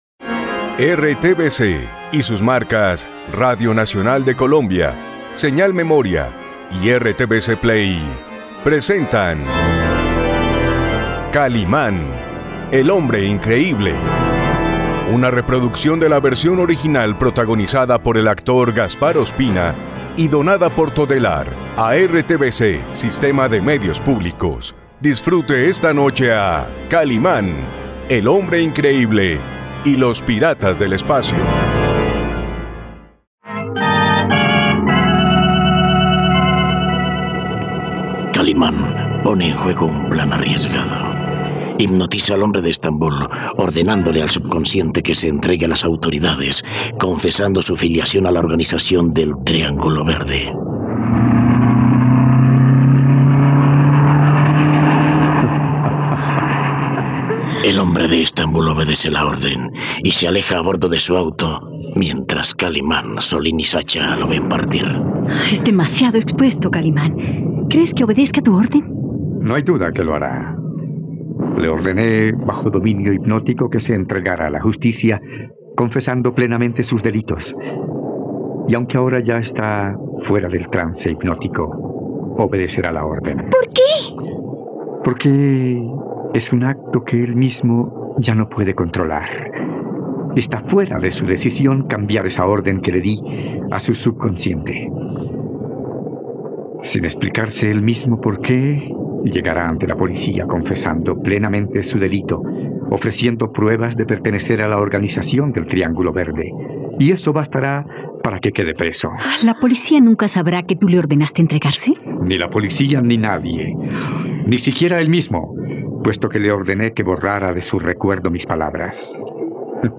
..No te pierdas la radionovela completa de Kalimán y los piratas del espacio.